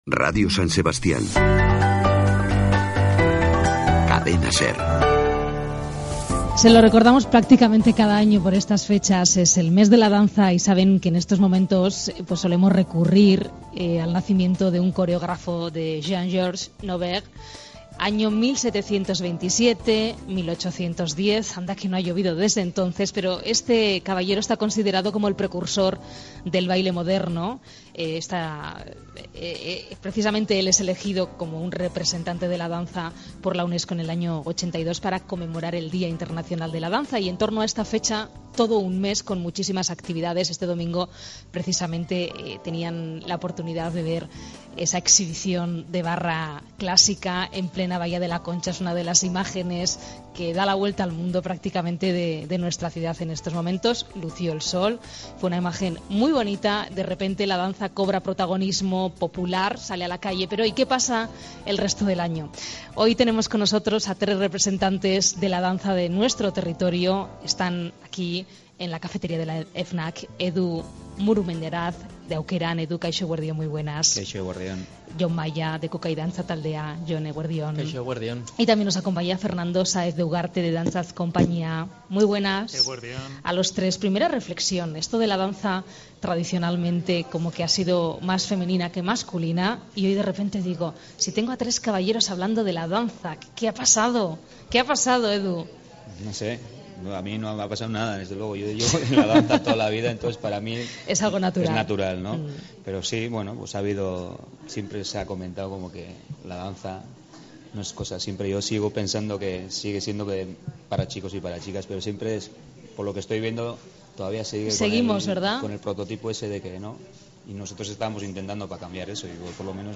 Radio San Sebastián (Cadena SER)-eko "Hoy por Hoy" irratsaioan, dantzari buruzko solasaldia